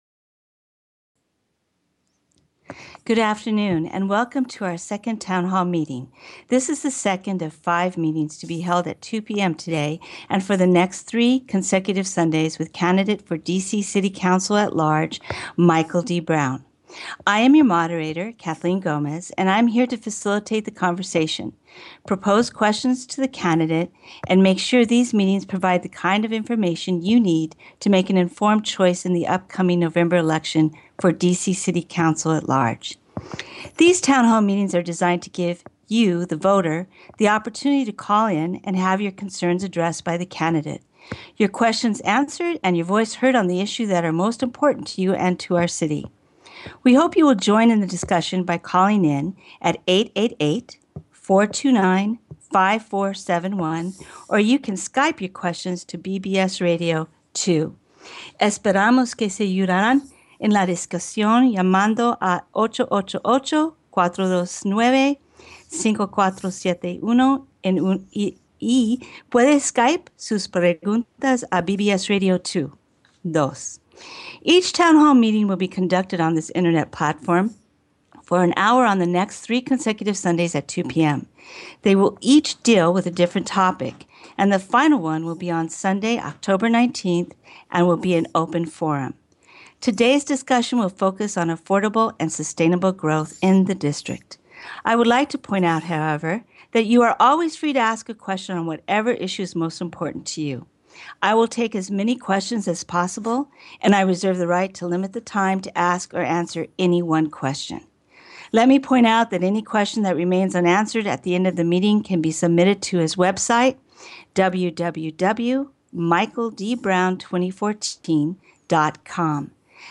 Town Hall Meetings with Senator Michael D. Brown